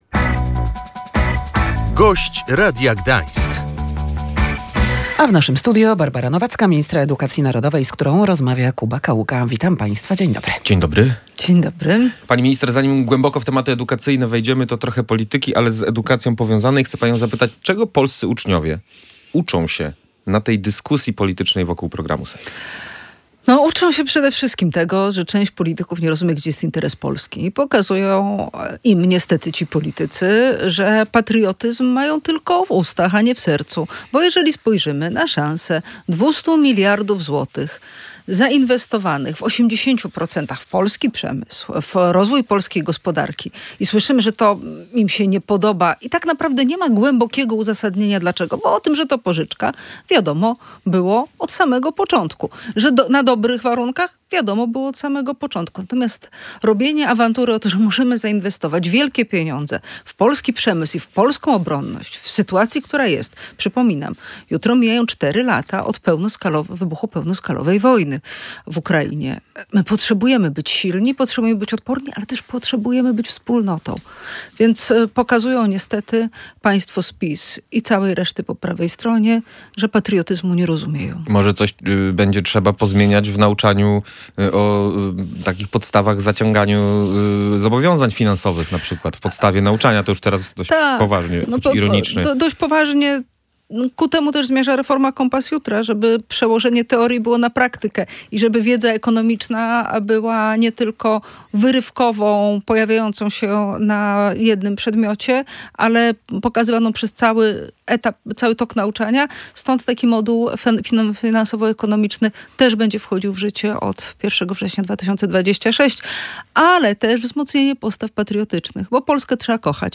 W tym tygodniu Koalicja Obywatelska przedstawi projekt ustawy dotyczący ograniczenia korzystania z mediów społecznościowych dla osób poniżej 15. roku życia – poinformowała w Radiu Gdańsk minister edukacji narodowej Barbara Nowacka.